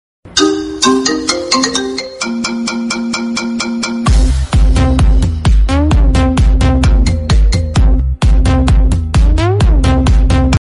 آهنگ زنگ موبایل رینگتون خفن لاتی ریمیکس